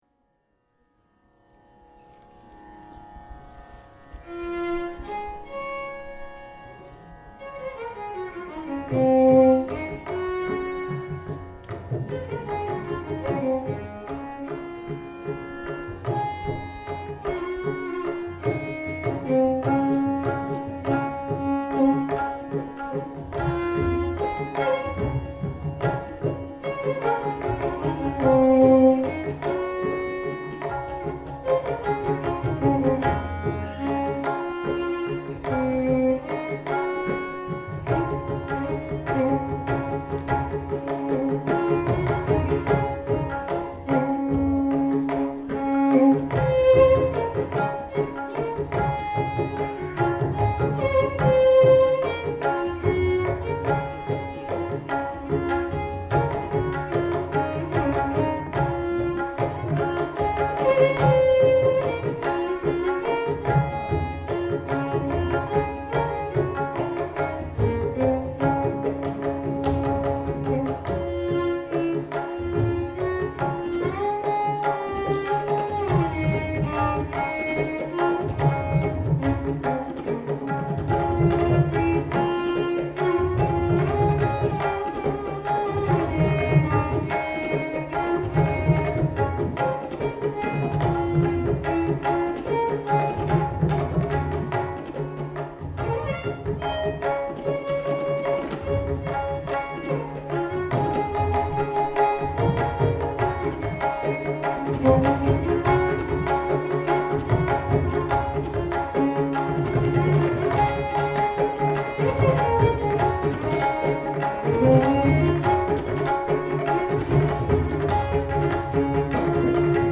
Indian Classical Music at Art In Action 2012
A piece of music performed by three blokes, one with a violin